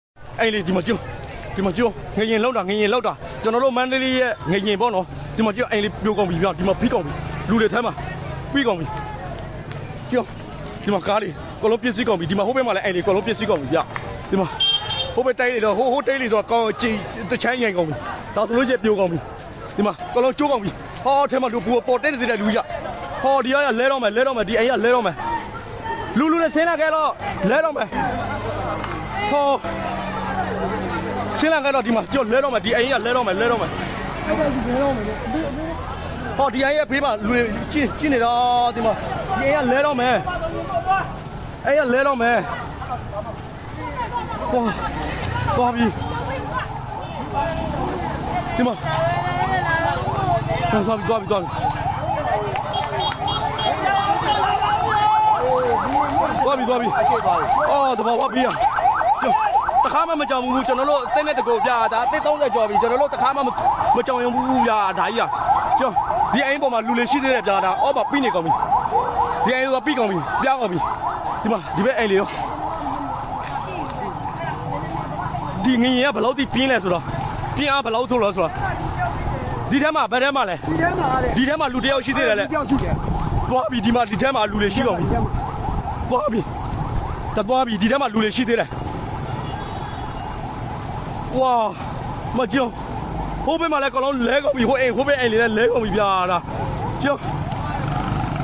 ငလျင်လှုတ်ခတ်နေစဉ်နှင့် လှုတ်ခတ်အပြီး မန္တလေးမြို့ မြို့တွင်း အခြေအနေများ